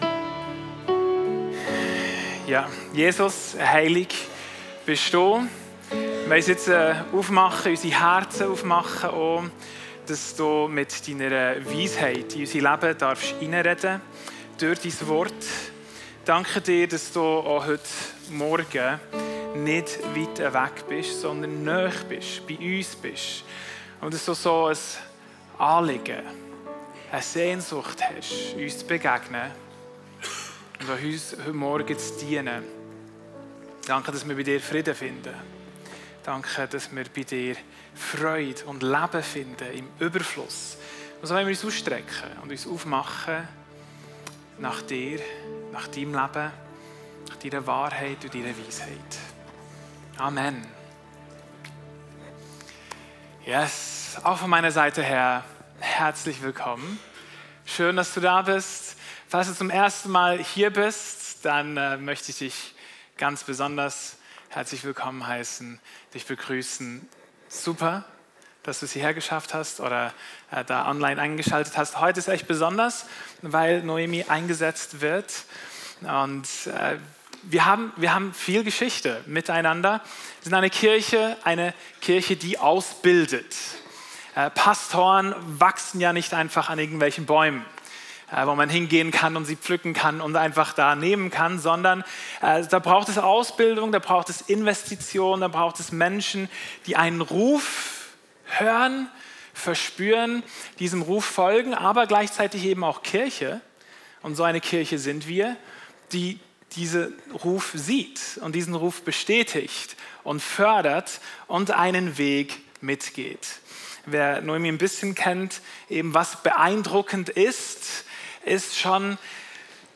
Audio+Predigt.m4a